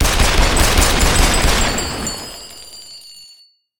shells.ogg